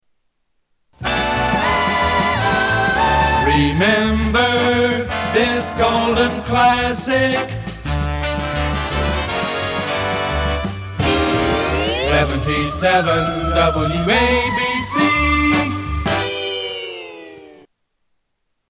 Tags: Radio Radio Stations Station I.D. Seques Show I.D